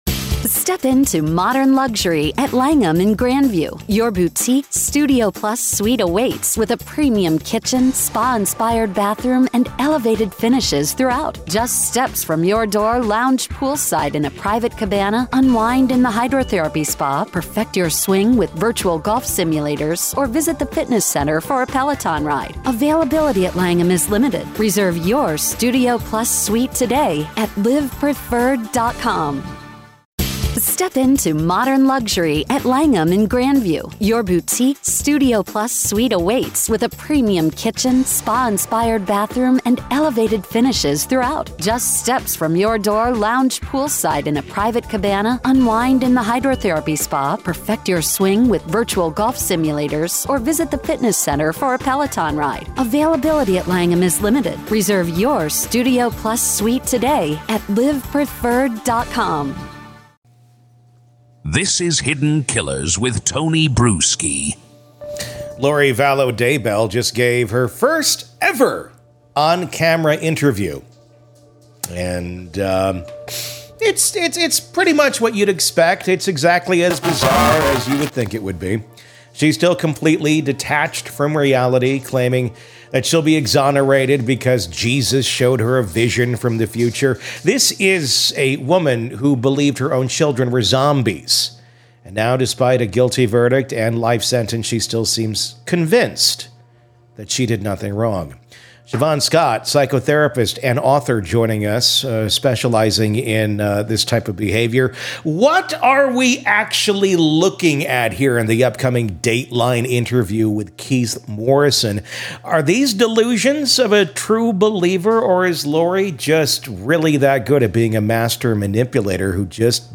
Psychotherapist and author